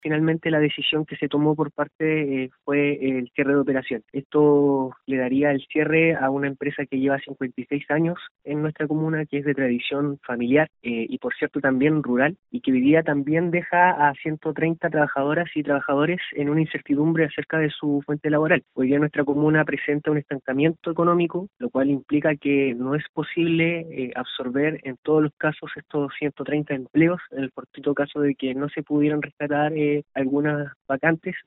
El concejal Pablo Vargas calificó la situación como un grave daño para la economía comunal e informó que el caso ya fue puesto en conocimiento del Municipio, el Gobierno y parlamentarios.
concejal.mp3